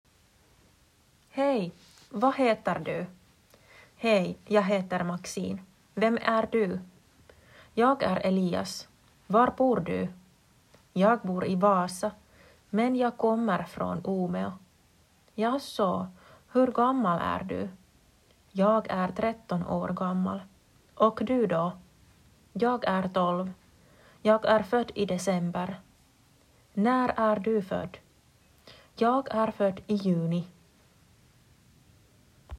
Opettajan malliäänitys
Vuoropuhelu TB s.14.m4a